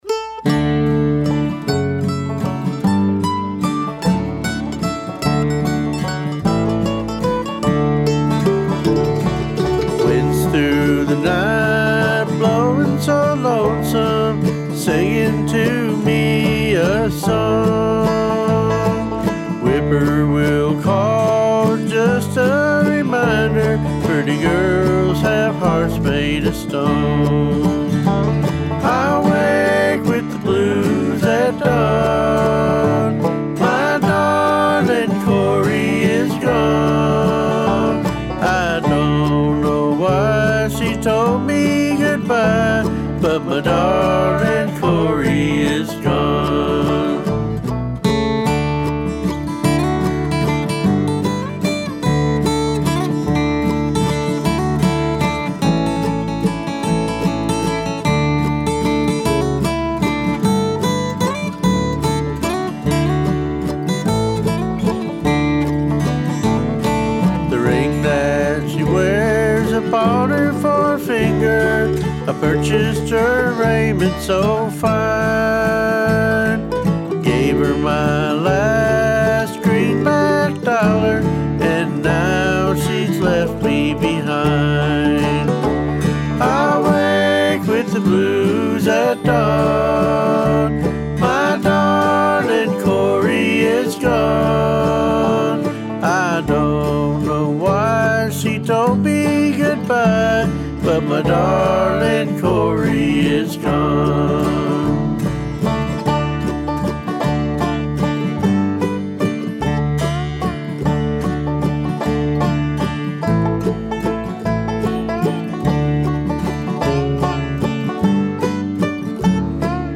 bluegrass acoustic song
For stereo micing the guitar, banjo and mandolin, I also used a SM81 straight into the interface pre.
I play acoustic bluegrass, and did all the parts on this song.
On the master I used compression, mid/side eq, stereo imager, saturation, limiter.
There's one chord that gets a bit muddy.
It felt just a little dense, and maybe the bass droned a little for my taste.
I kind of lied about the instruments - for bass, I'm using an electric bass and not an acoustic bass. Everything else is acoustic.
Plus, you get a stereo image from 2 mics you don't get from mono.